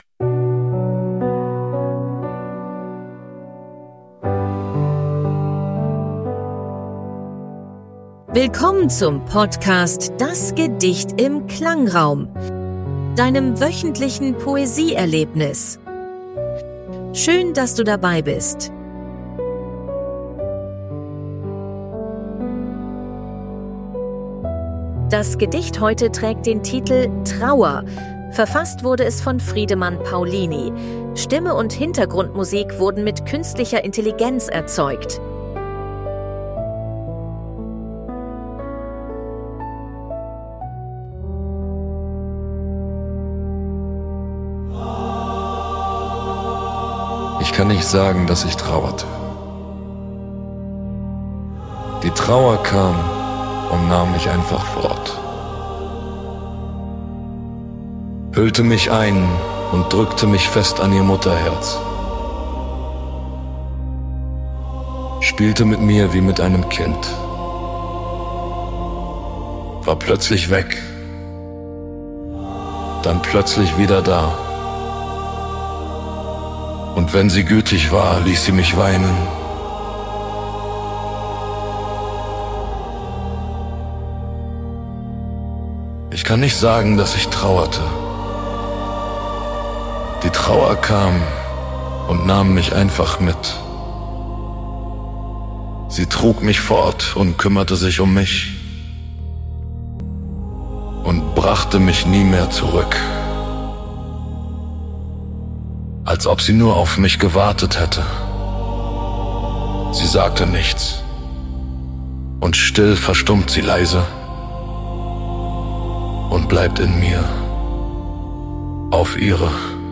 Hintergrundmusik wurden mit KI erzeugt. 2025 GoHi (Podcast) -